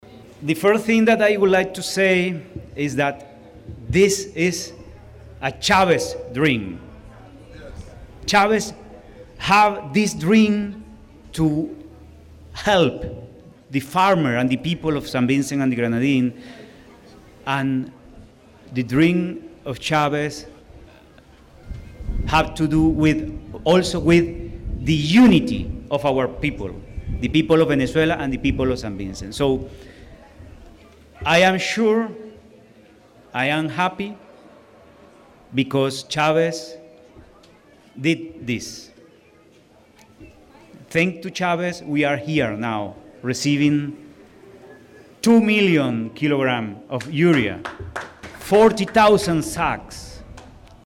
The Distribution Ceremony was held at the La Croix Palletization Centre, with addresses from several officials, including Minister of Agriculture, Saboto Caesar.